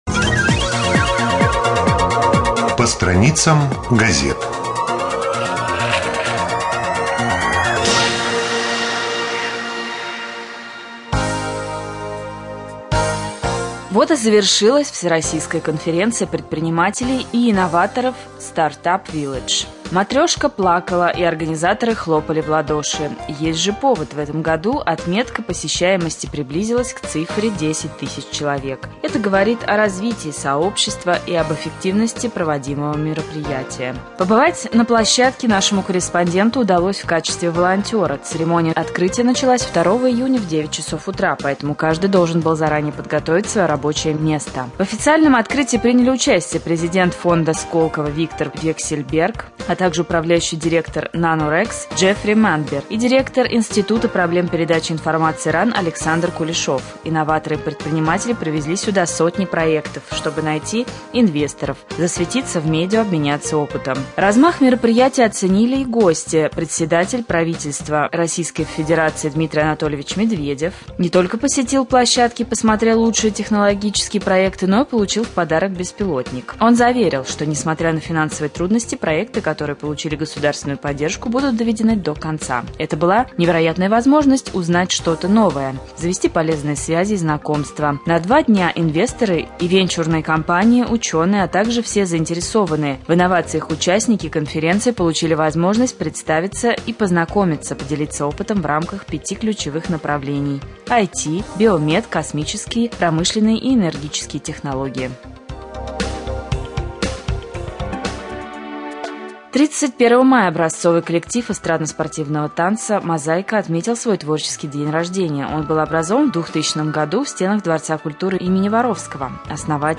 25.06.2014г. в эфире раменского радио - РамМедиа - Раменский муниципальный округ - Раменское